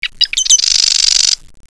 wren2.wav